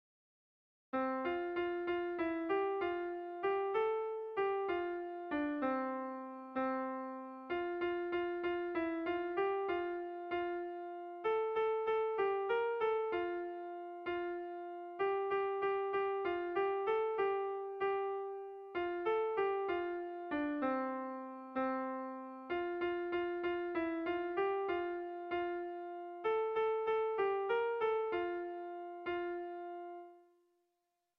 Irrizkoa
Zortziko txikia (hg) / Lau puntuko txikia (ip)
A1A2BA2